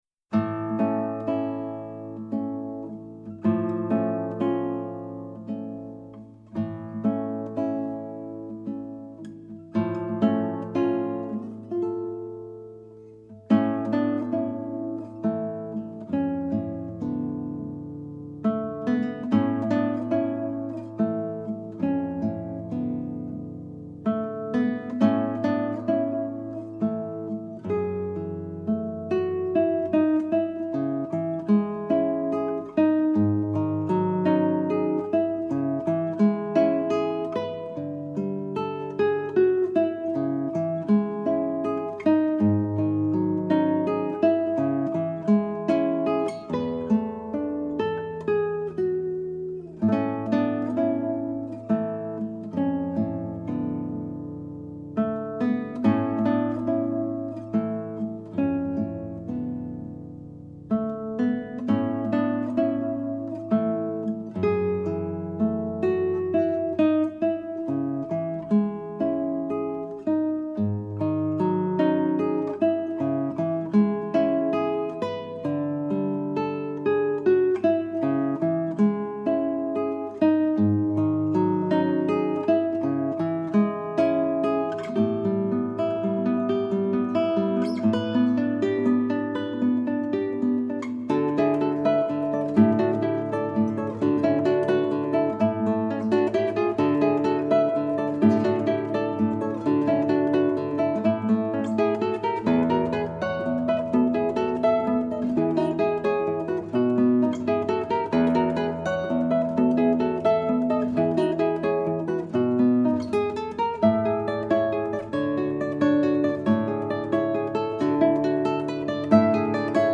beautiful contemporary three movement work
II. Adagio